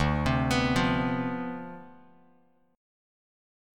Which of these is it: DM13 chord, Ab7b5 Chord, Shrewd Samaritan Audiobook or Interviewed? DM13 chord